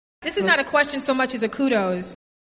In the US it’s even possible to hear kudos used as a countable singular, but pronounced with a final z like a plural: